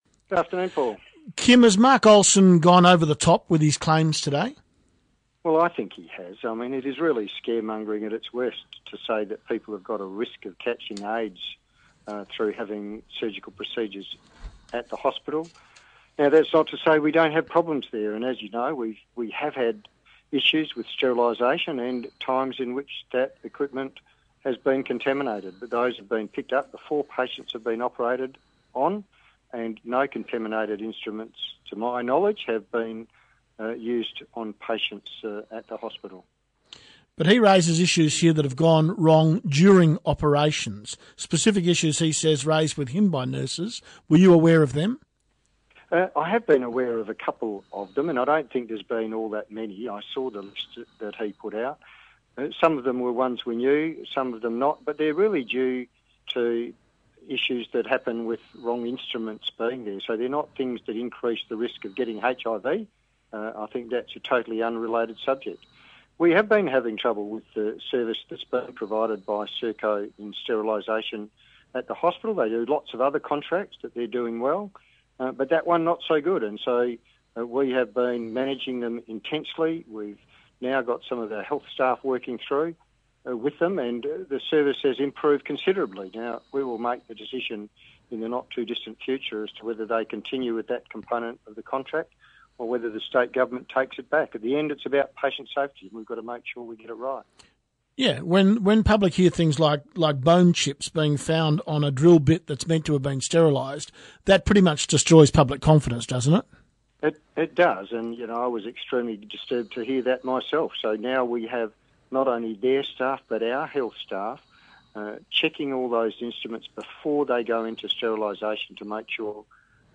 State Health Minister Kim Hames responds to calls for his resignation over claims that surgical equipment is going missing or unsterilised.